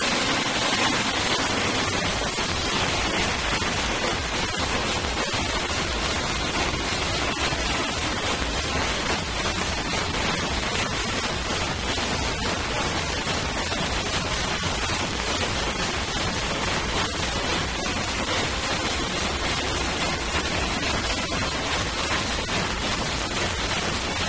AHH-- the bad sound quality is killing my ears